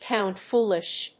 アクセントpóund‐fóolish